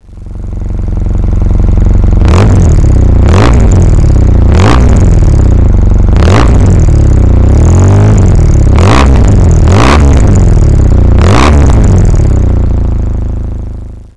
Klicka för att höra Bonneville med effektljuddämpare
Triumph Bonneville på 790 cc och cirka 70hk. Paralleltwin